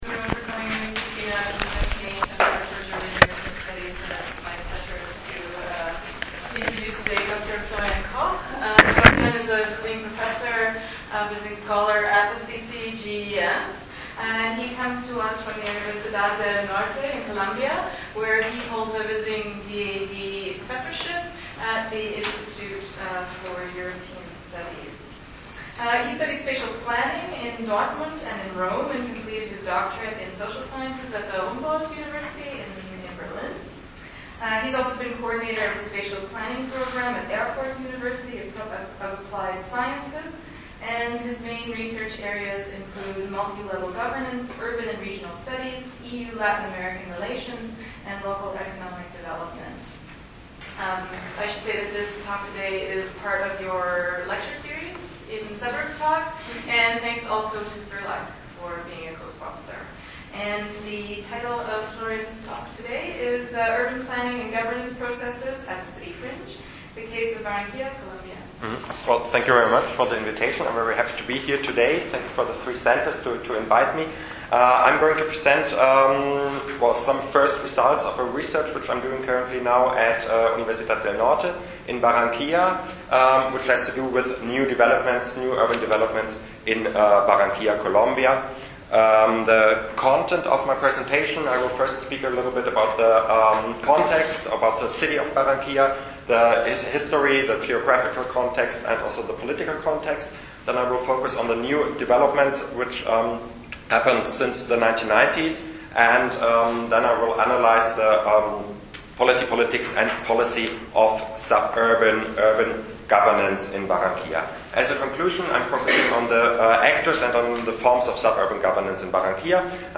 SUBURBTALKS- Seminar